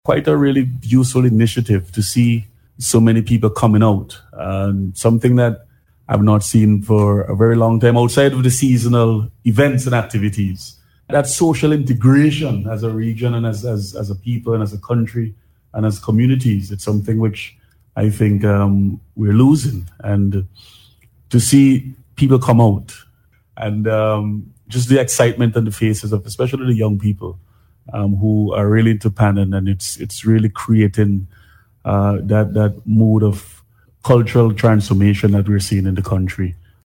Minister of Tourism and Parliamentary Representative for North Leeward, Carlos James, welcomes the initiative.